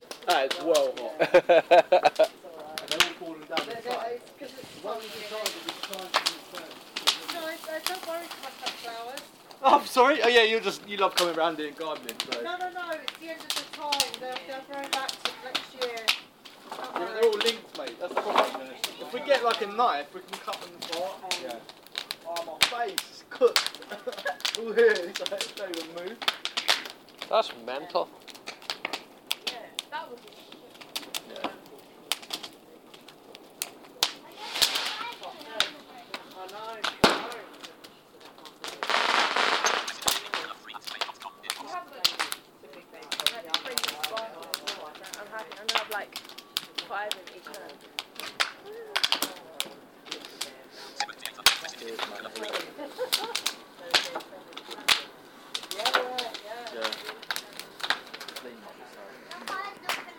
fire and fireworks